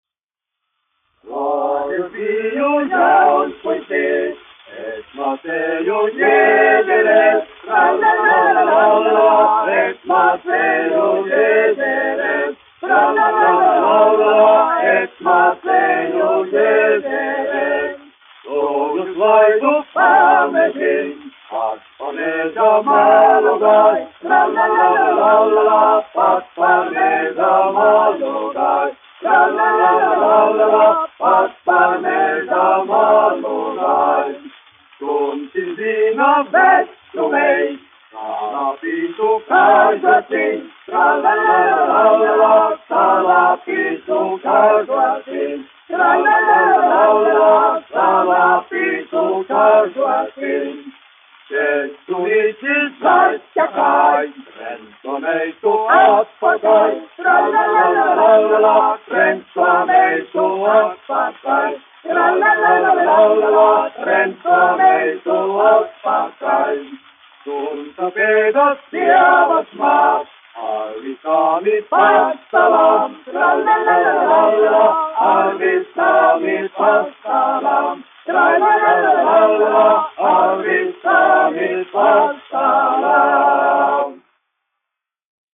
Piltenes prāģeri (mūzikas grupa), aranžētājs, izpildītājs
1 skpl. : analogs, 78 apgr/min, mono ; 25 cm
Latviešu tautasdziesmu aranžējumi
Vokālie seksteti
Skaņuplate